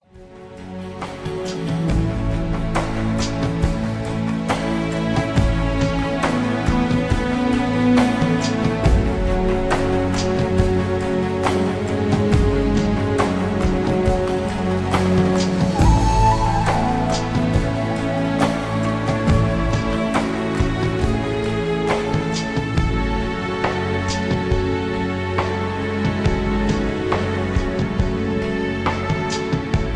(Key-A)
Just Plain & Simply "GREAT MUSIC" (No Lyrics).
mp3 backing tracks